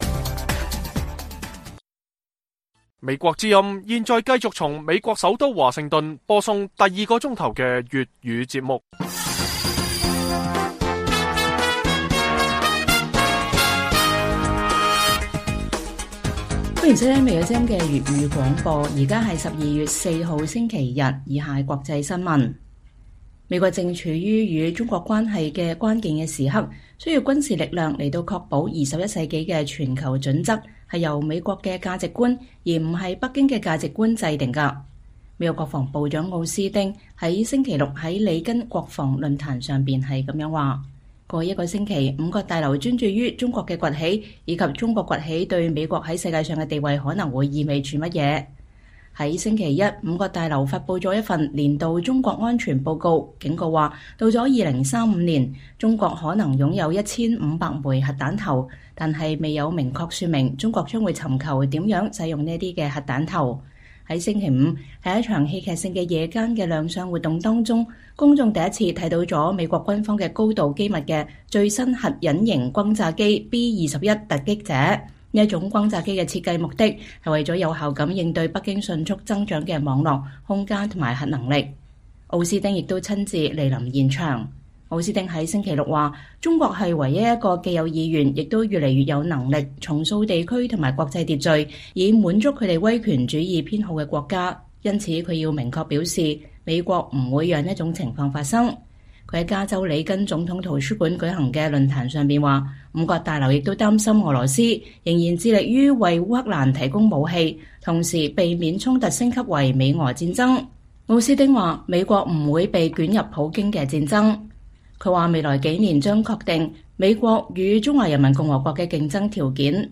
粵語新聞 晚上10-11點：美國情報稱：習近平無視抗議的威脅 拒絕接受西方國家的疫苗